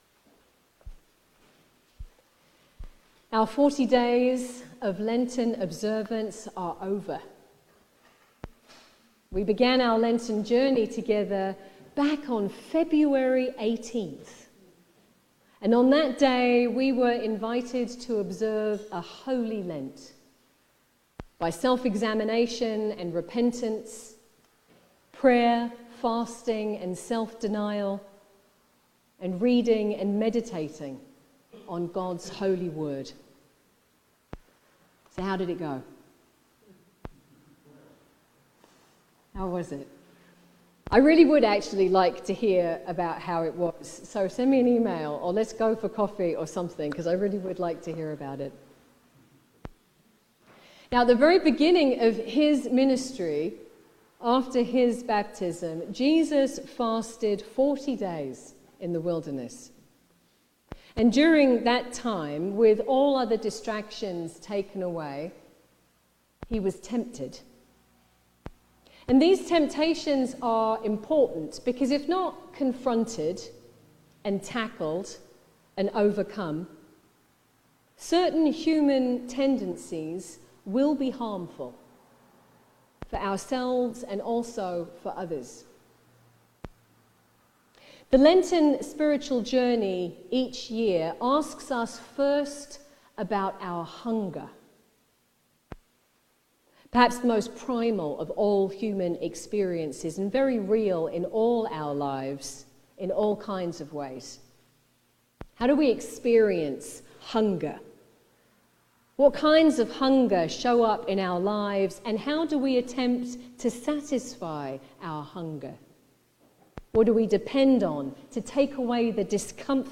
Easter-Service-Sermon.mp3